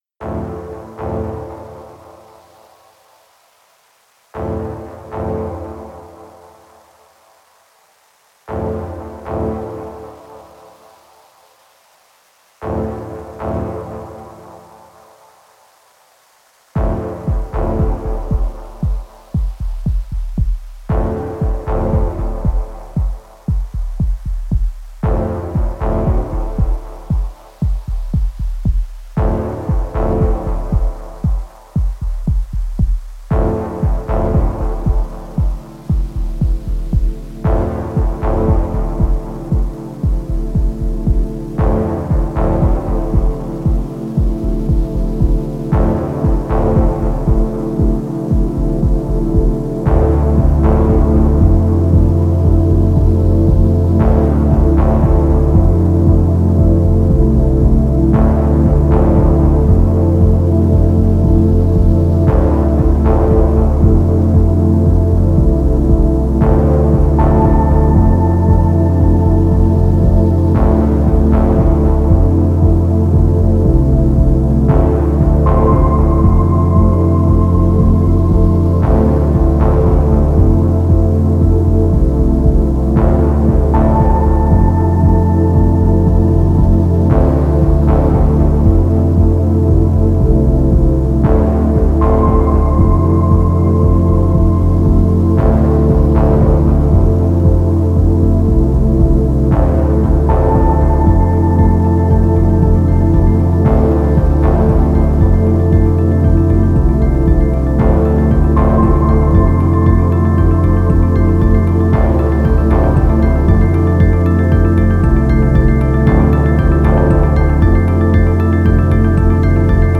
Genre: Ambient/Deep Techno/Dub Techno/Electro.